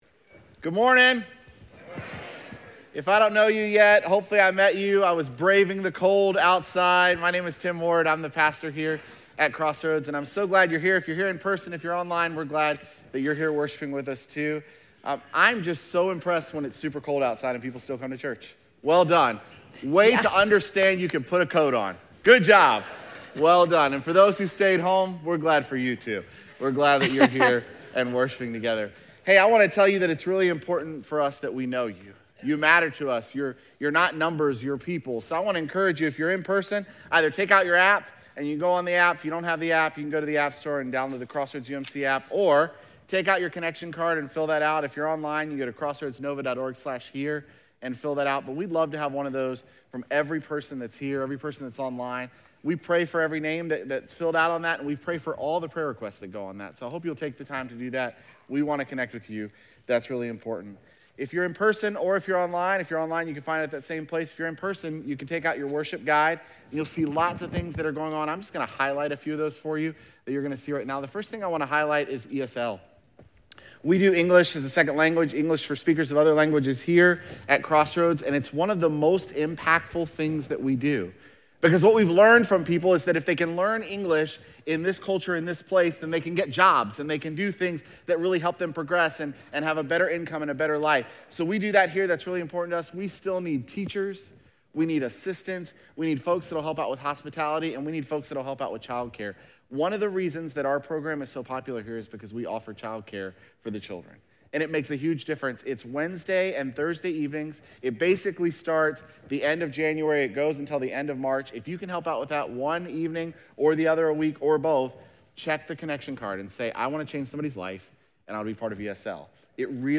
jan15sermon.mp3